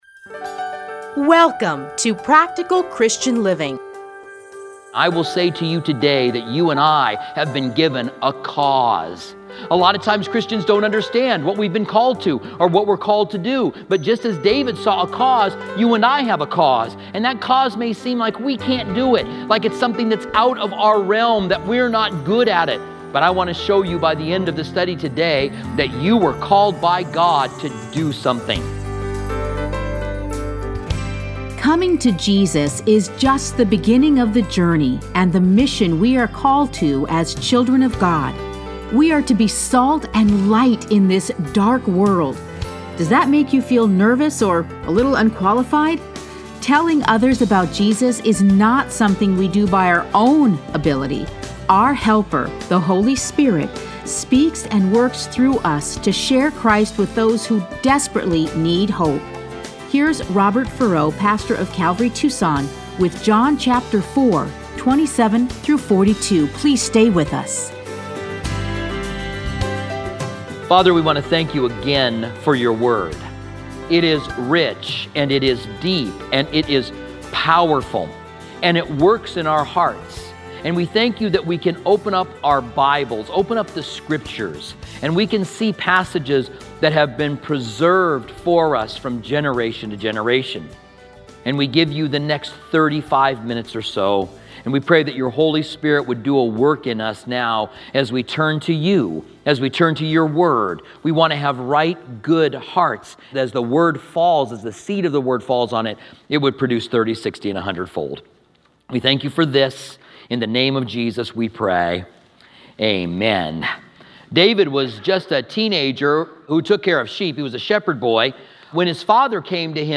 Listen to a teaching from John 4:27-42.